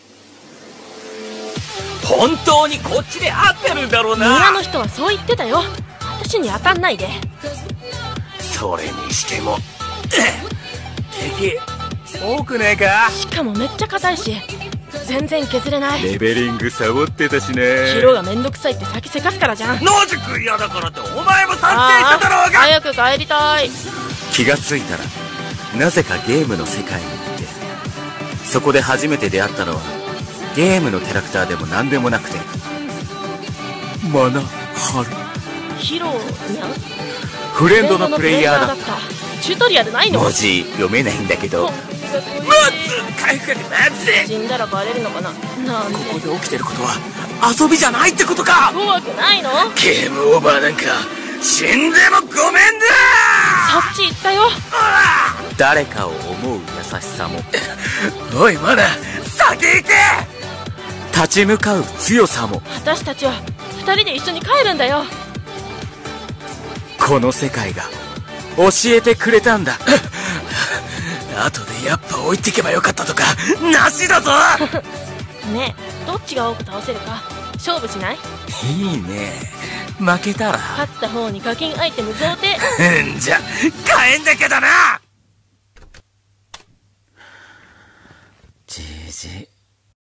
2人声劇